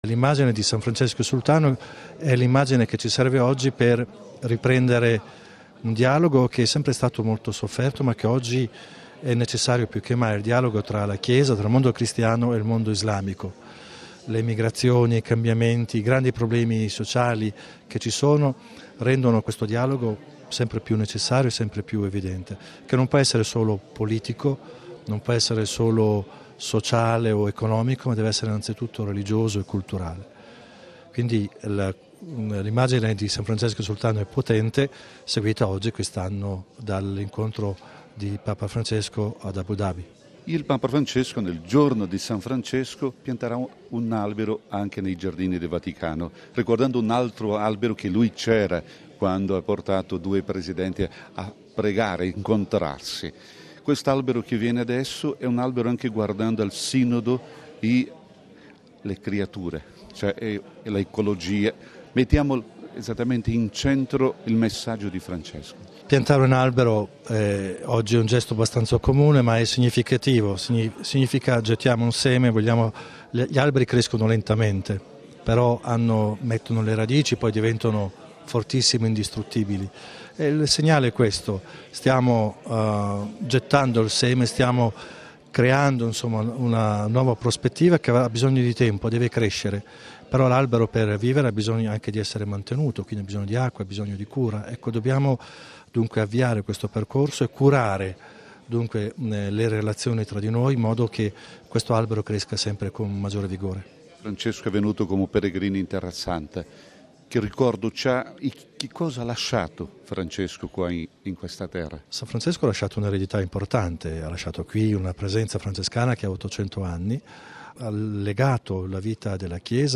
Clicca qui per ascolltare l'intervista all'arcivescovo Pizzaballa